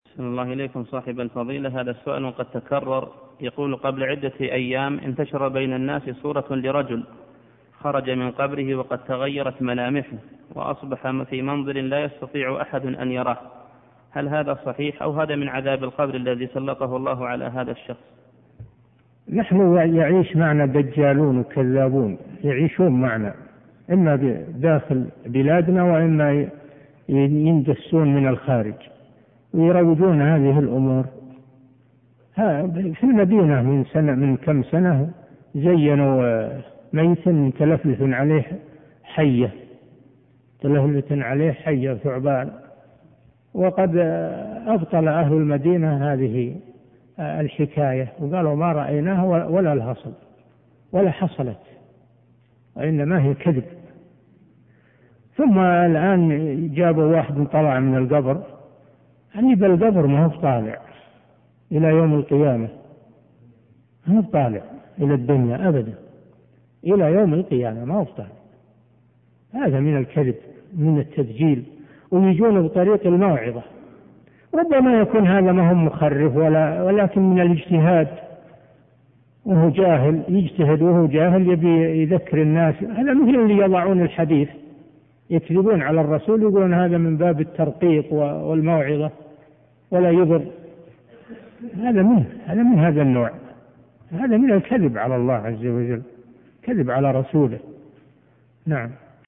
المصدر / فتوى الشيخ صالح الفوزان في قرص الفتى المعذب المزعوم ضمن درس شرح السنة للبربهاري بتاريخ 20 محرم 1427
الشيخ قال مهوب بس اللي فرغ يمكن ما عرفه فكتب مش وأرسلت للمشرف ليعدل الفتوى
وهذه فتوى الشيخ بالصوت